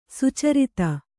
♪ sucarita